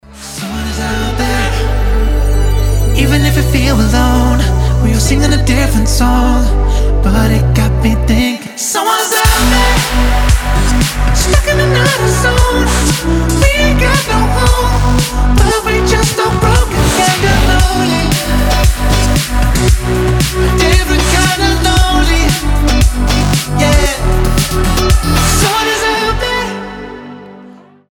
• Качество: 320, Stereo
громкие
Electronic
Downtempo
future house
космические